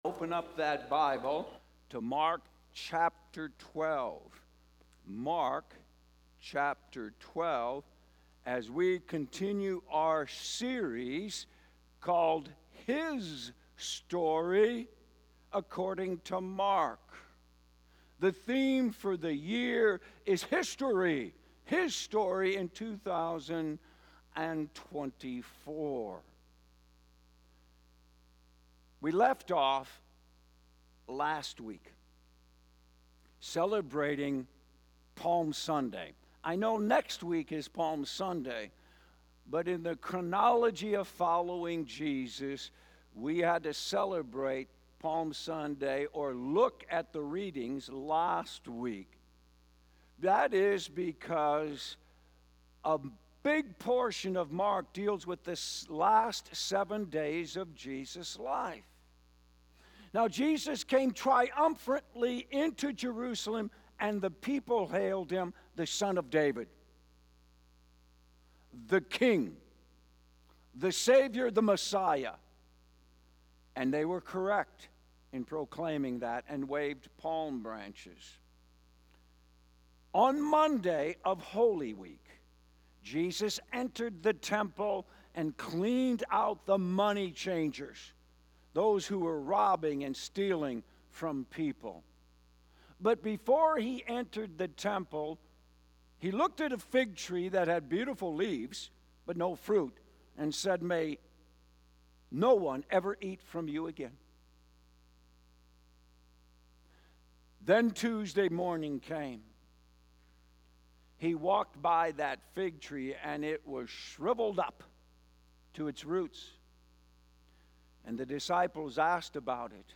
Sunday morning message from the series HIStory in 2024 according to Mark